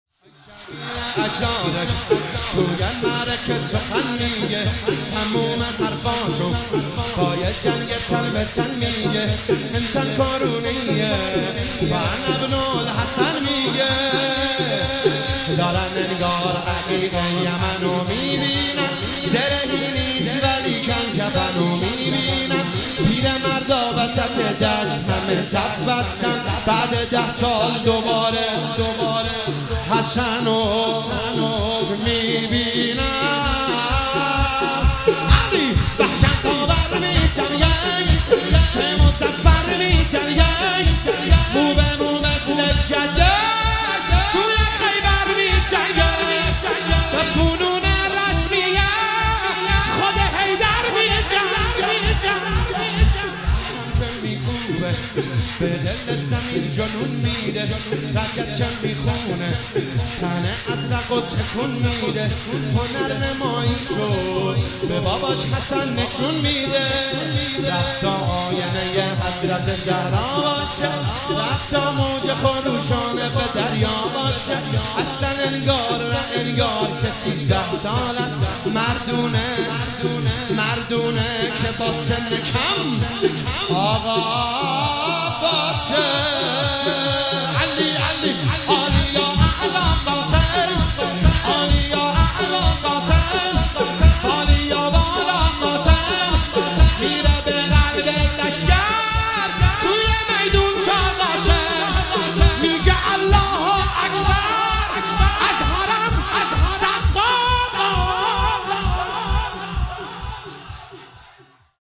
شهادت حضرت رقیه(س) |هیئت ام ابها قم